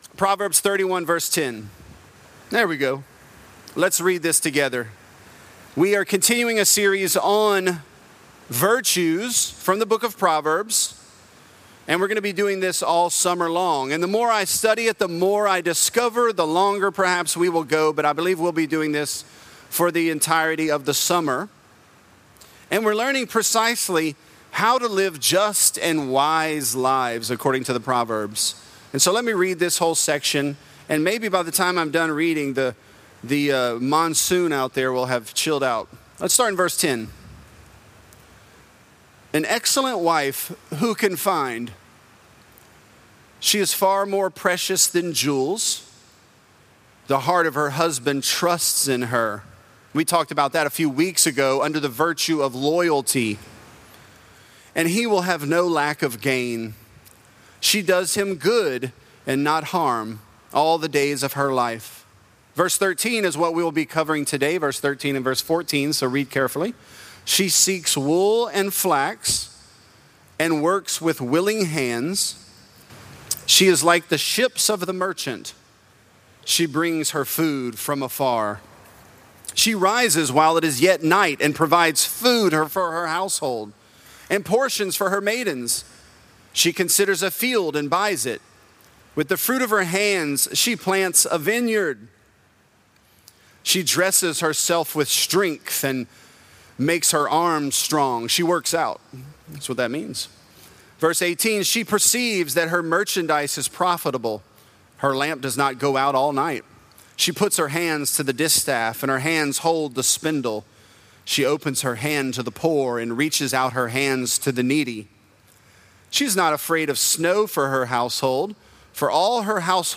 This is a part of our sermon series, "Virtuous."